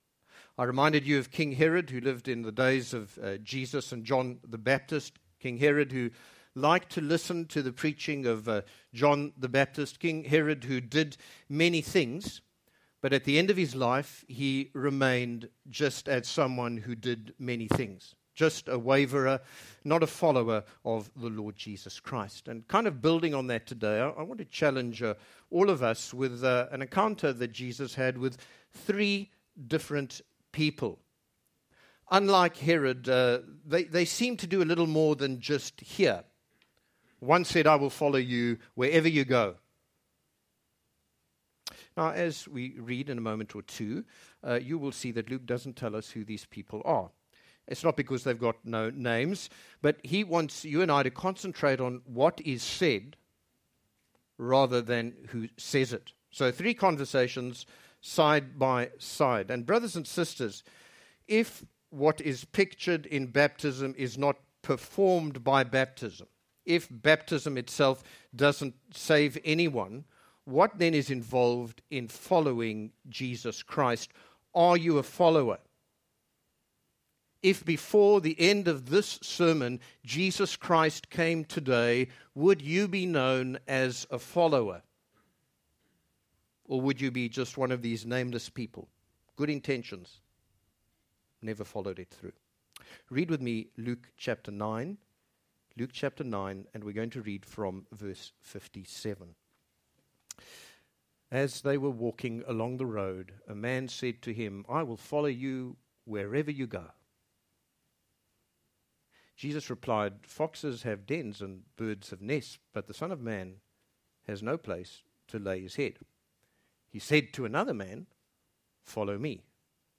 – International Baptist Church (IBC) of Brussels